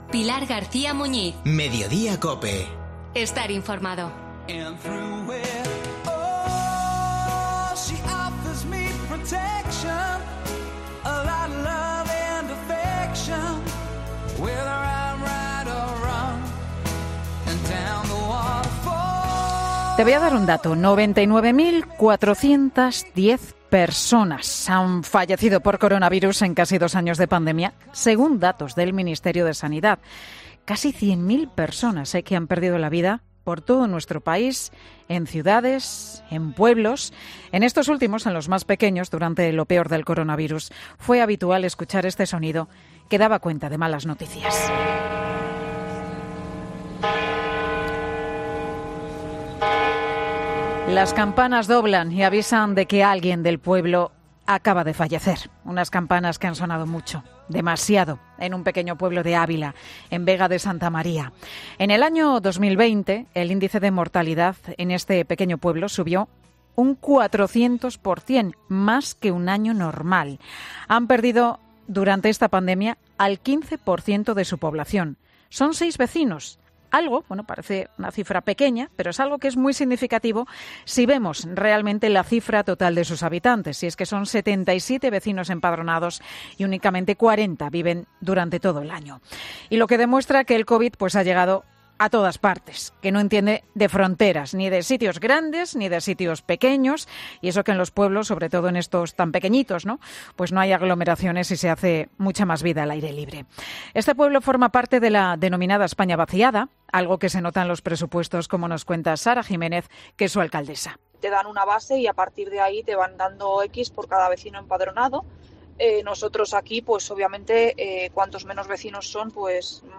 vecino de Vega de Santa María